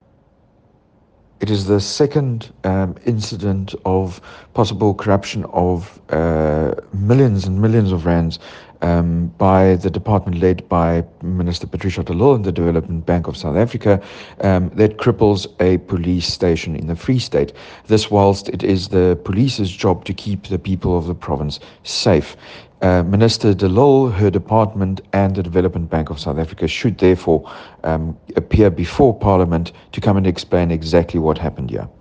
English and Afrikaans soundbites by George Michalakis MP
Voicenote-Eng-convert.mp3